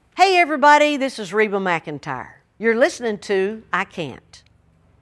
LINER Reba McEntire (I Can't) 4